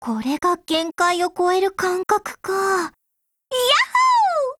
贡献 ） 协议：Copyright，其他分类： 分类:语音 、 分类:少女前线:P2000 您不可以覆盖此文件。